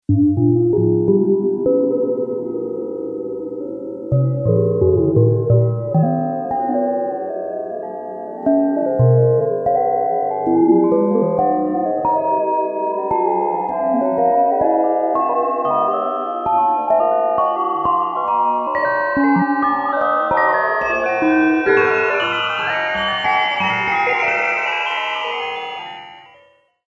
scary-chimes_14223.mp3